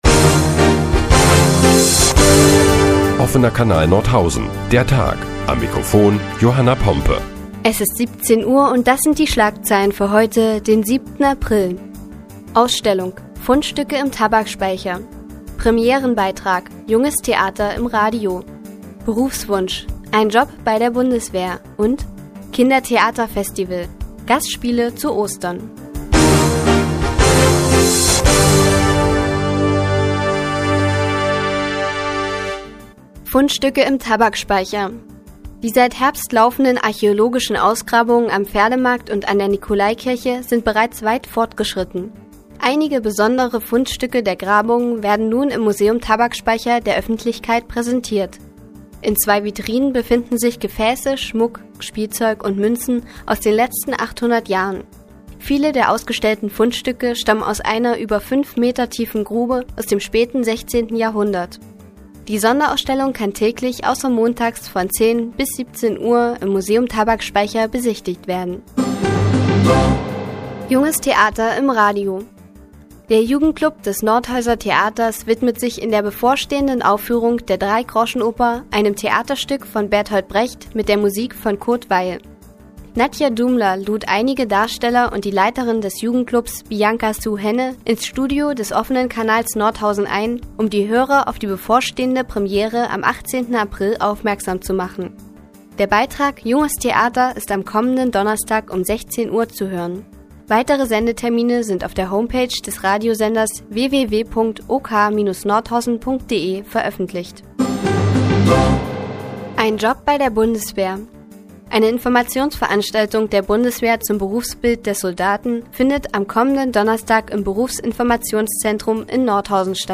Die tägliche Nachrichtensendung des OKN ist nun auch in der nnz zu hören. Heute geht es unter anderem um Fundstücke im Tabakspeicher und das Junge Theater im Radio.